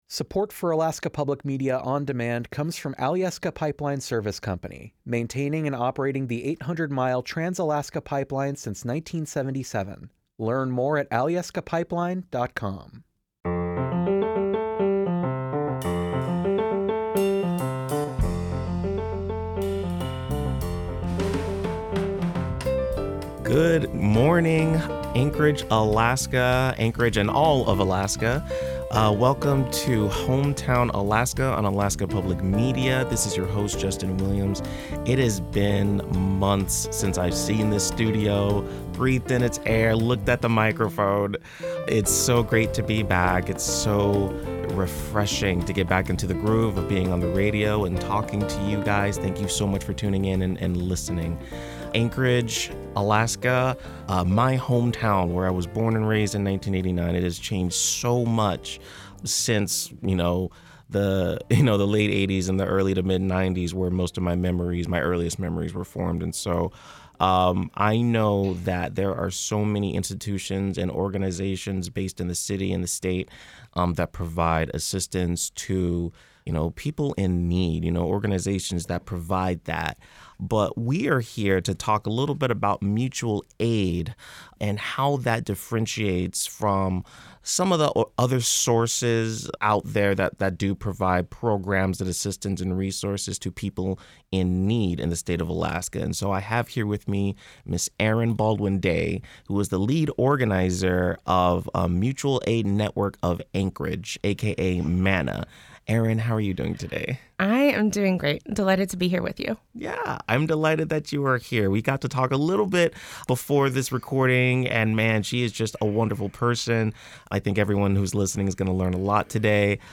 Hometown, Alaska features conversations with leaders and decision-makers in local and statewide government, social service agencies, educational institutions, and cultural groups across Anchorage and Alaska.